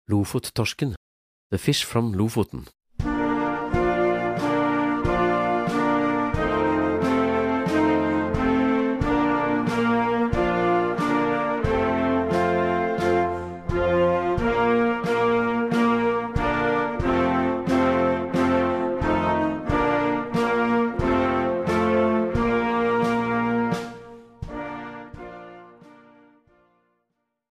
Gattung: Jugendblasorchester
Besetzung: Blasorchester